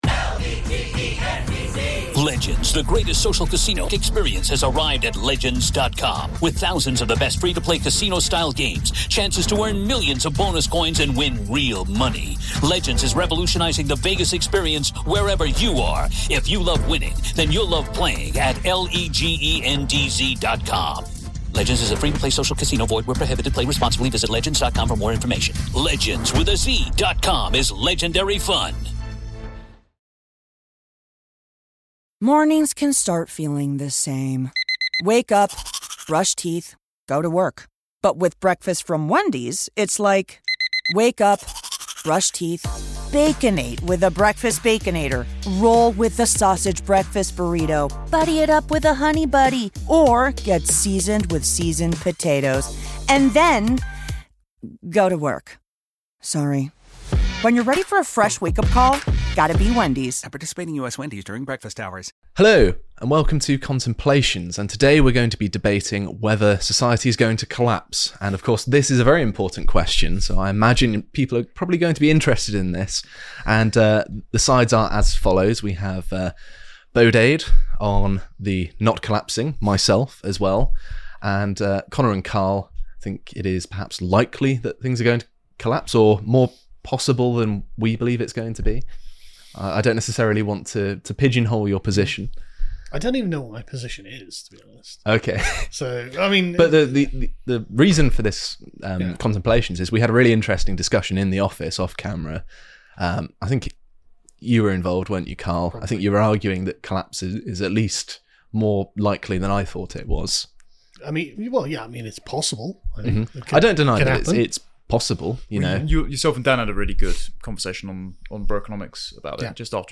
PREVIEW: Contemplations #152 | Debate: Is Society Going to Collapse?